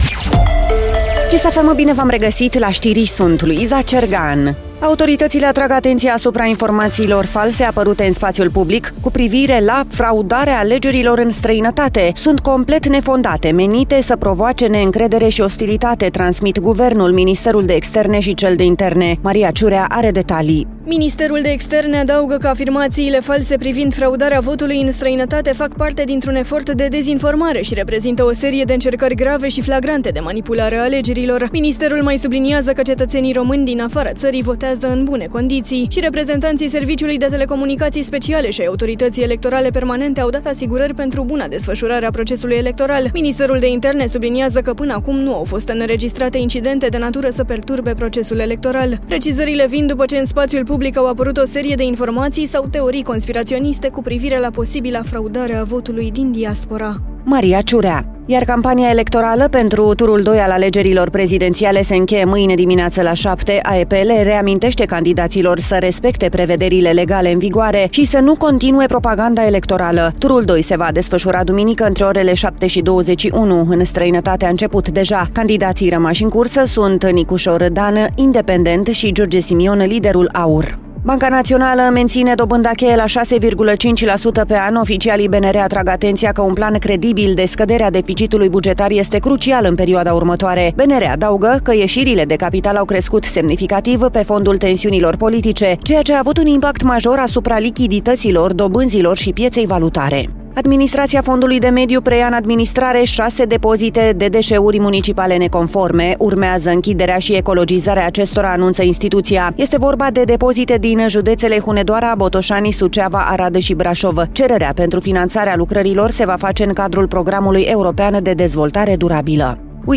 Știrile zilei de la Kiss FM Kiss News - 16 Mai, 18:00